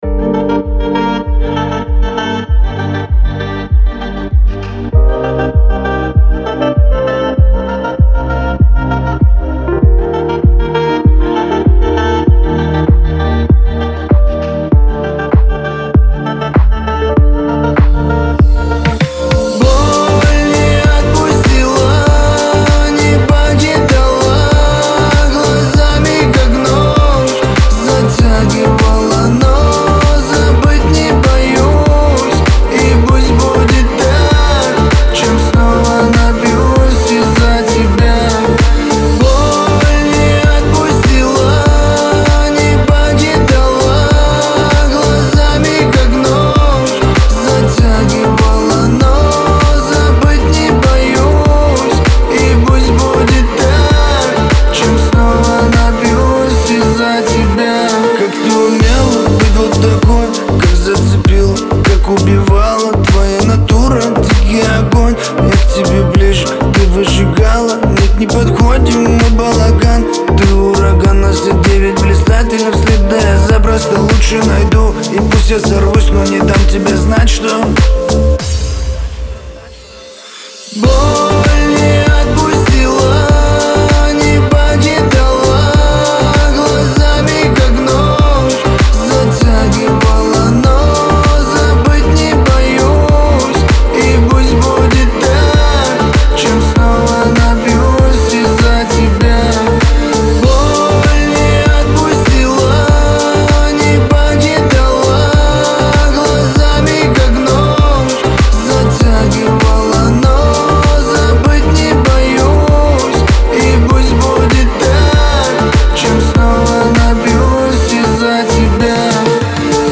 Кавказ – поп